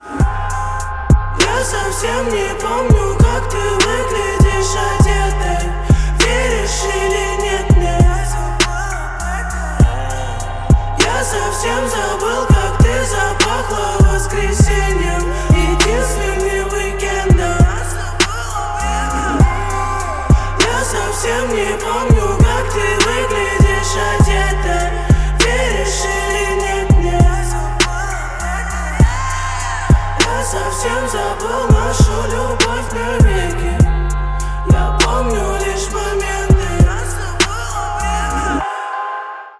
• Качество: 128, Stereo
Хип-хоп
грустные
русский рэп